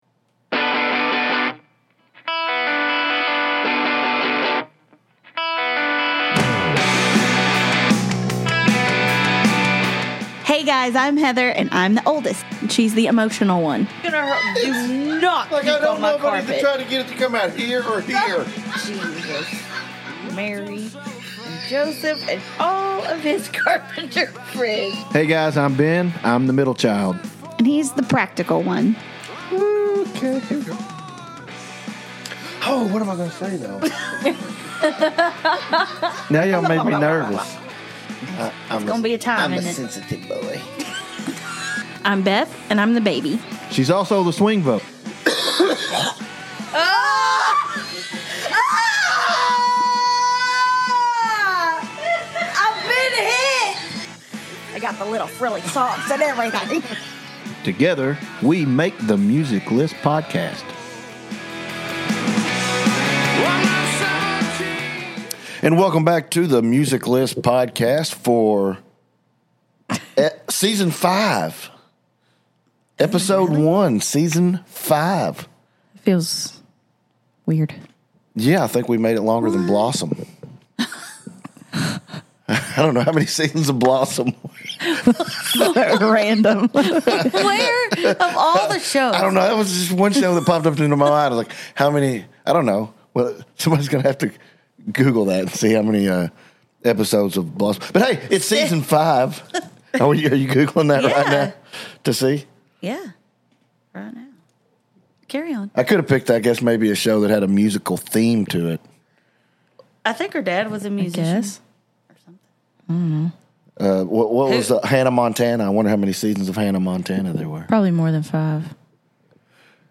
The Music List Podcast takes a recipe for entertainment- a hotly debated musical topic, three siblings who love to argue, and open mics, and then tosses them into a mixing bowl.